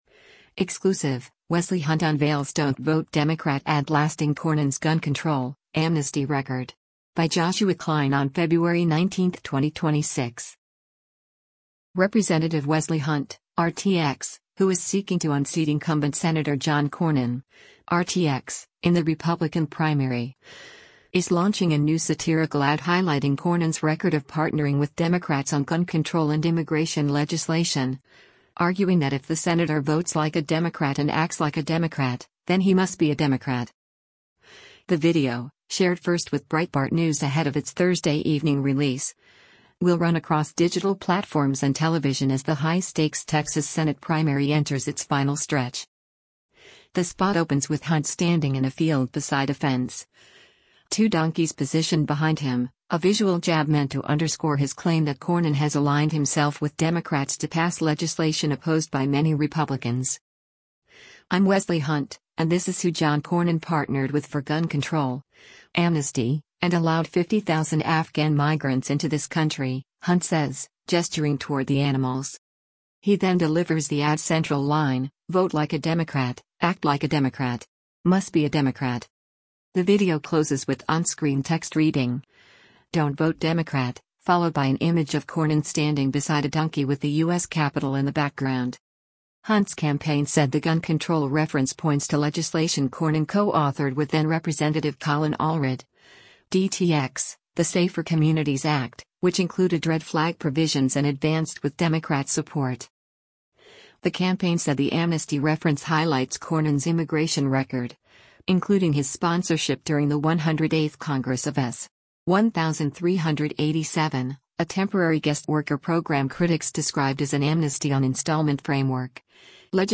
Rep. Wesley Hunt (R-TX), who is seeking to unseat incumbent Sen. John Cornyn (R-TX) in the Republican primary, is launching a new satirical ad highlighting Cornyn’s record of partnering with Democrats on gun control and immigration legislation — arguing that if the senator “votes like a Democrat” and “acts like a Democrat,” then “he must be a Democrat.”
The spot opens with Hunt standing in a field beside a fence, two donkeys positioned behind him — a visual jab meant to underscore his claim that Cornyn has aligned himself with Democrats to pass legislation opposed by many Republicans.